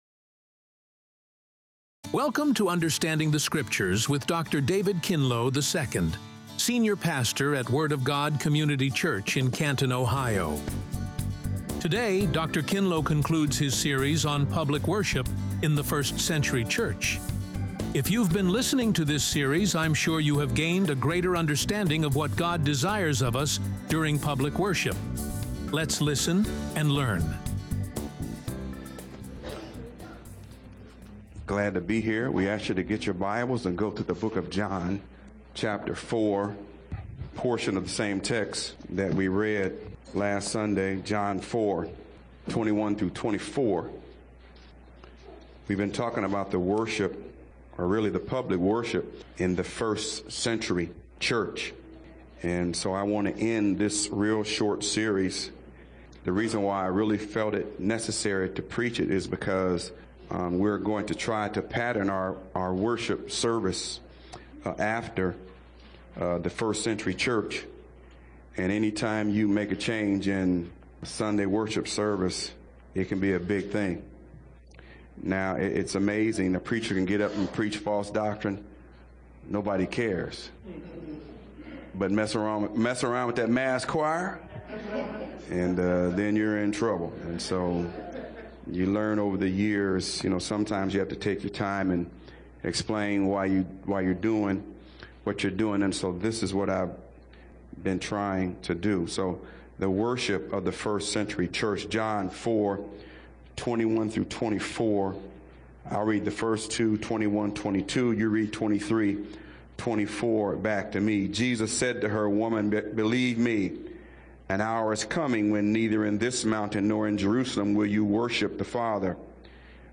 RADIO SERMON